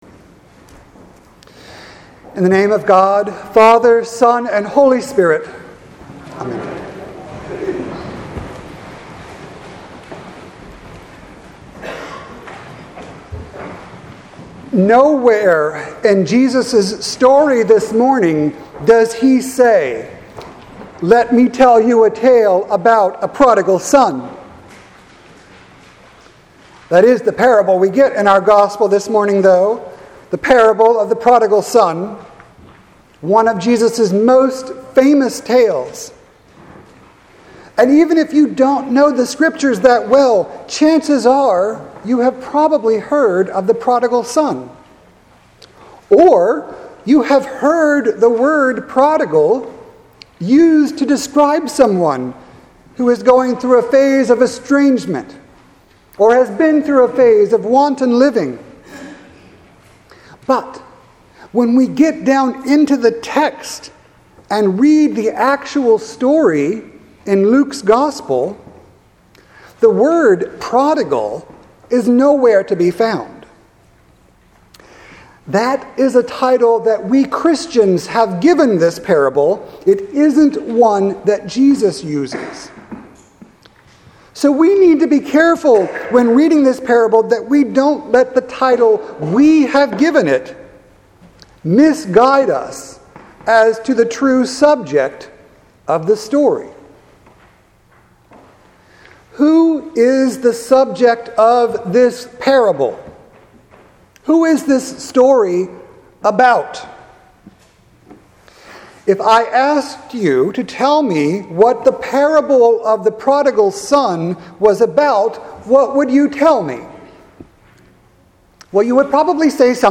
sermon-3-31-19.mp3